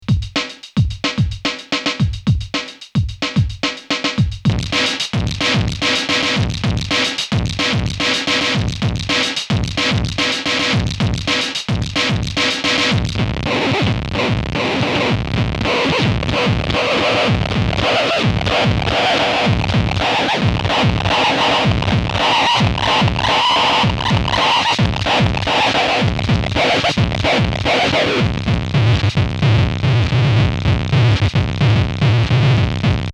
The added gain changes a few things about the PU: In "normal" mode, it's more of a fuzzbox, in "starve" mode, there's less gating, and in "osc" mode, there's more control over whether or not it's oscillating.